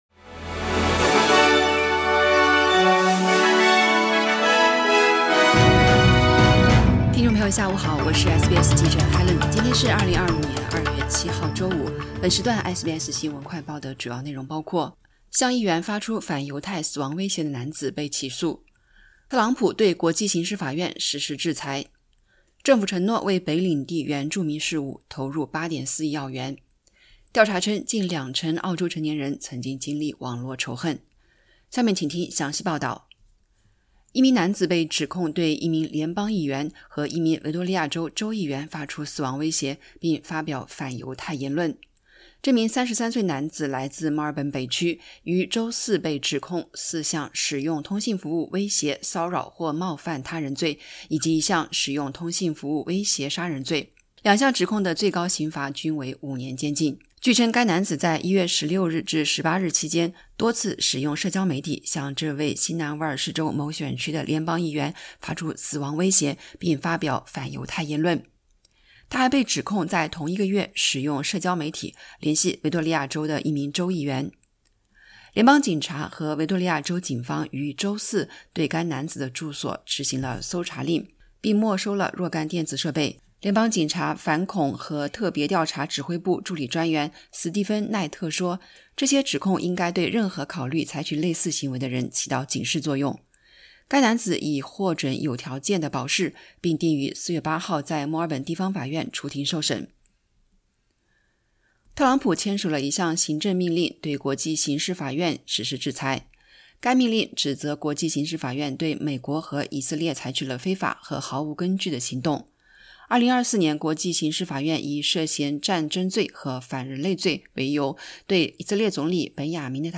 【SBS新闻快报】一男子因向议员发出反犹太死亡威胁而被起诉